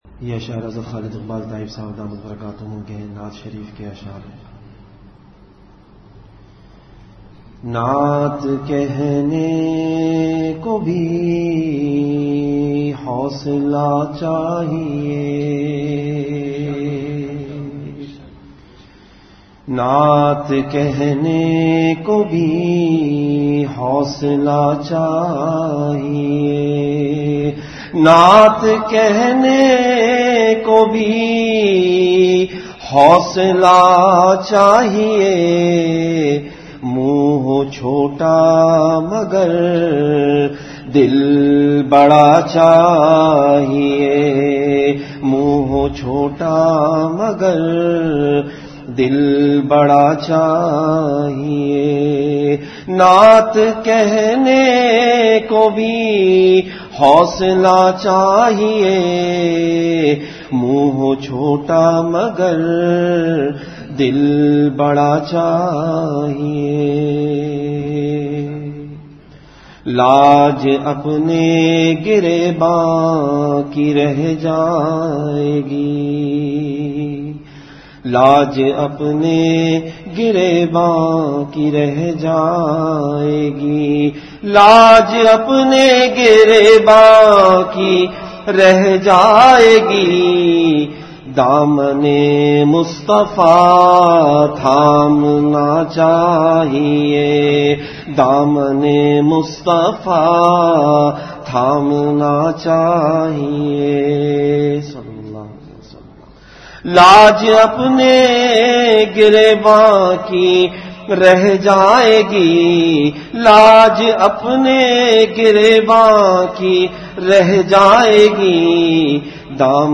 Delivered at Home.
Majlis-e-Zikr
After Magrib Prayer